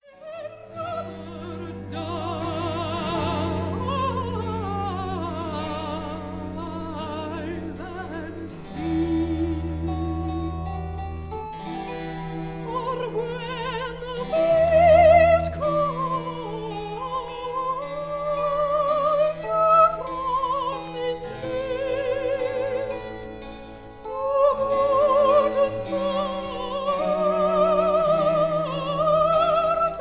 soprano
harpischord
cello